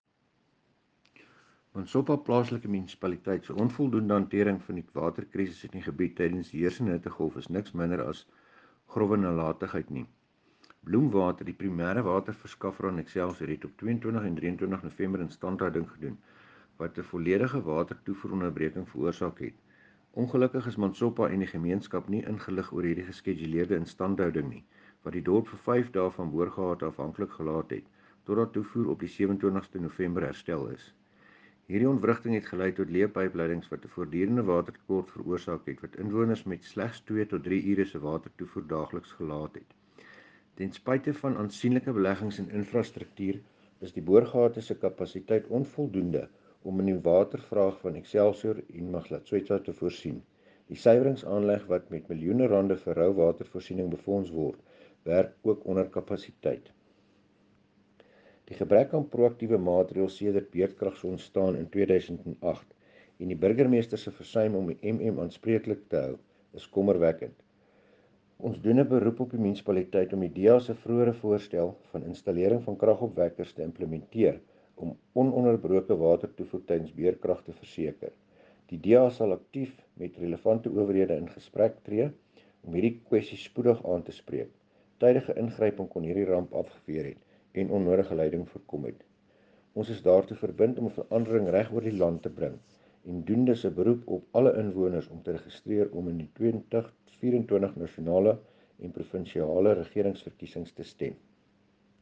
Afrikaans by Cllr Dewald Hattingh and